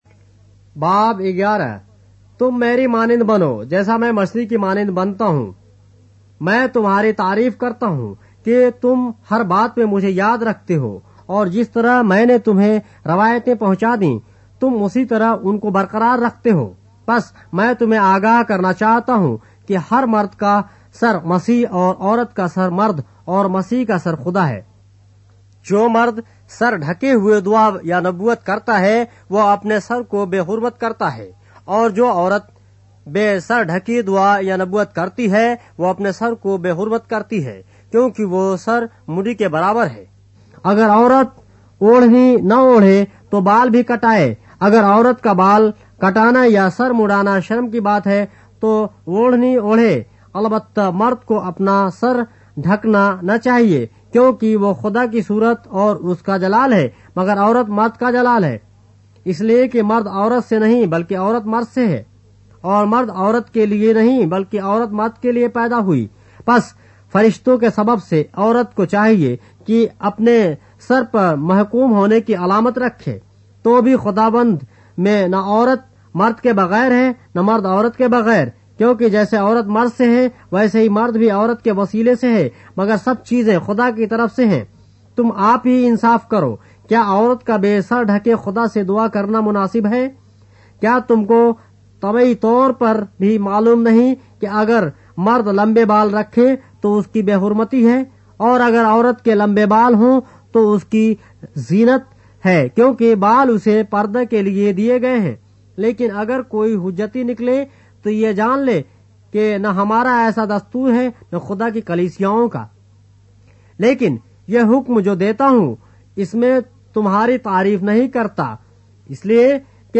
اردو بائبل کے باب - آڈیو روایت کے ساتھ - 1 Corinthians, chapter 11 of the Holy Bible in Urdu